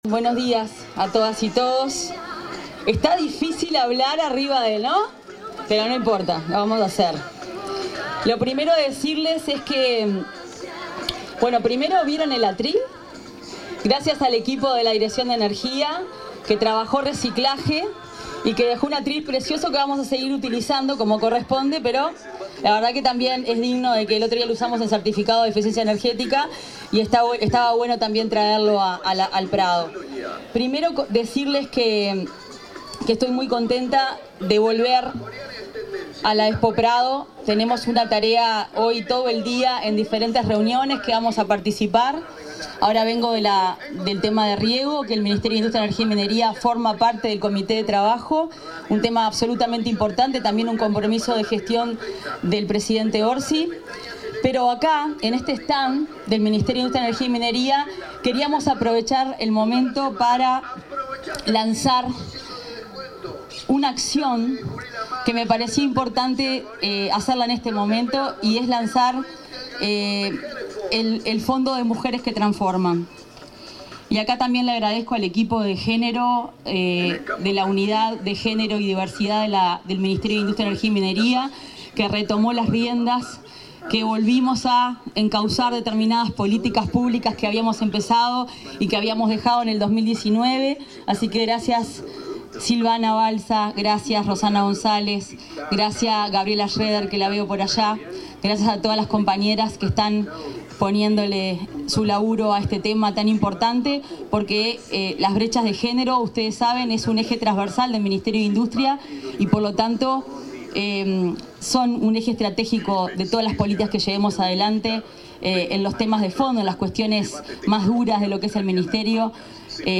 Palabras de la ministra de Industria, Energía y Minería, Fernanda Cardona
La titular del Ministerio de Industria, Energía y Minería (MIEM), Fernanda Cardona, disertó en la presentación del fondo Mujeres que Transforman 2025,
Palabras de la ministra de Industria, Energía y Minería, Fernanda Cardona 09/09/2025 Compartir Facebook X Copiar enlace WhatsApp LinkedIn La titular del Ministerio de Industria, Energía y Minería (MIEM), Fernanda Cardona, disertó en la presentación del fondo Mujeres que Transforman 2025, realizada durante la inauguración de un stand de esa secretaría de Estado en la Expo Prado 2025.